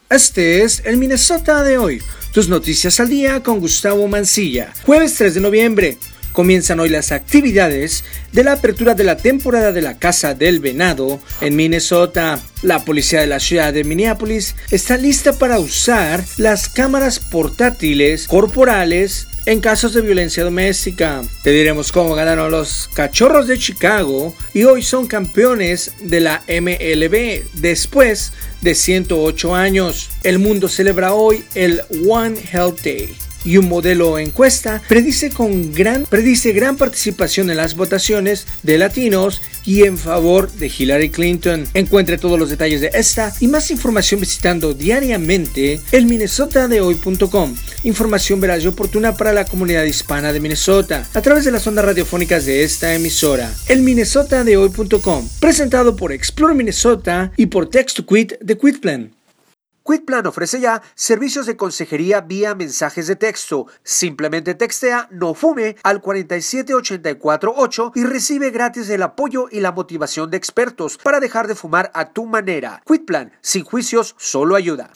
MNHoy Radio Capsula